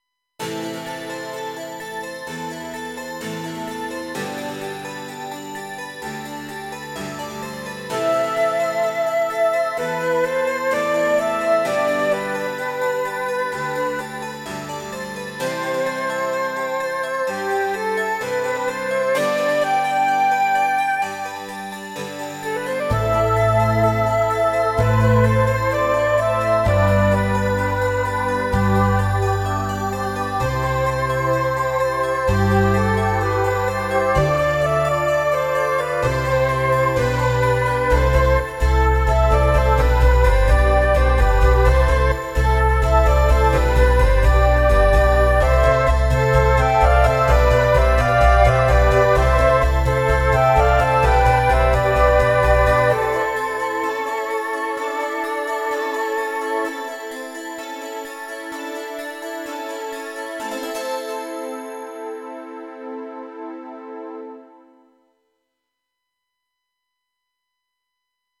私のオリジナル曲のうち、ゲームミュージック風の曲を公開いたします。